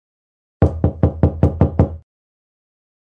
Knocking